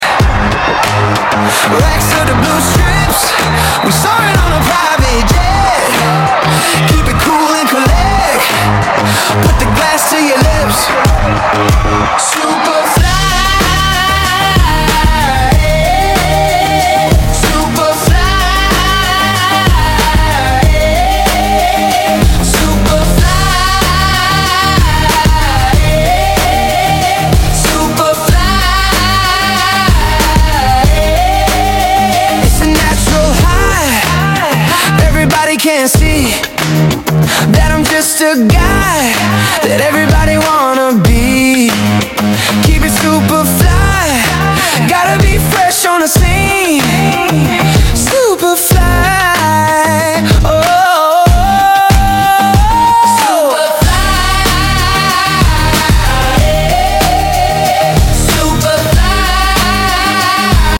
A floating big dog sound effects free download